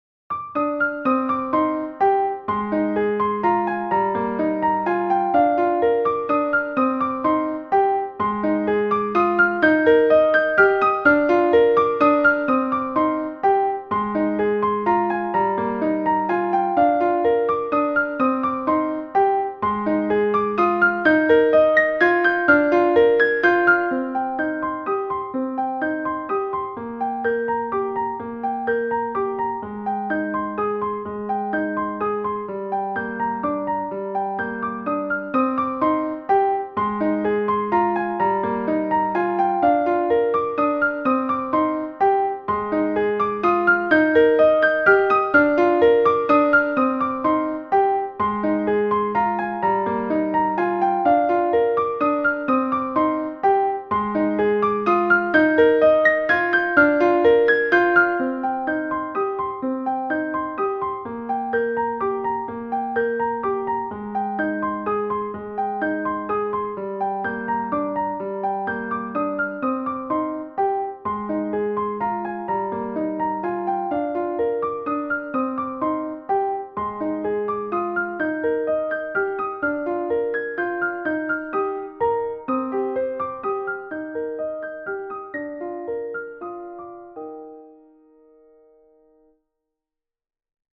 Lovely, gentle, easy piano piece.
Classical Period, Instructional, Romantic Period